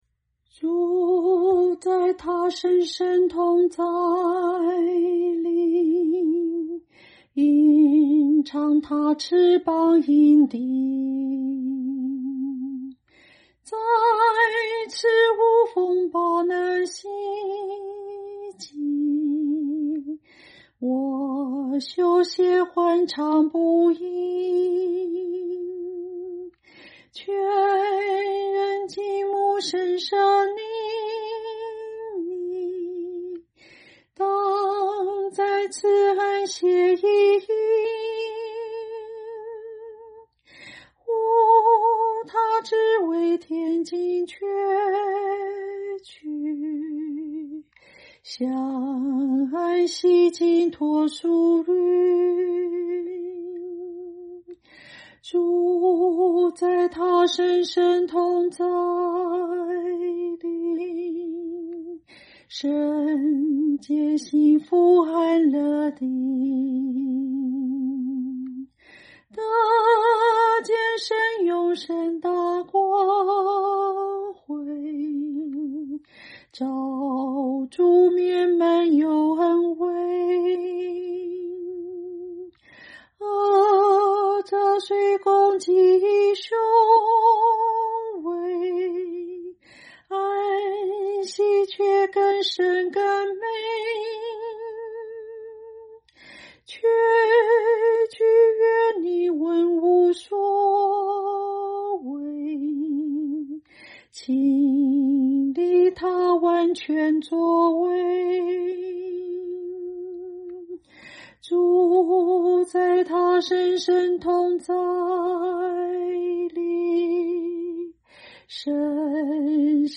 清唱    伴奏